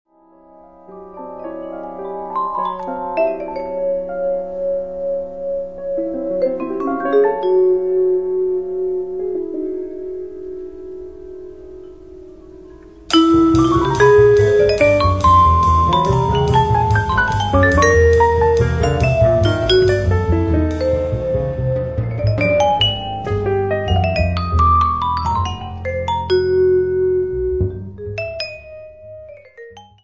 The album’s an excellent quartet session
vibes
piano
bass
drums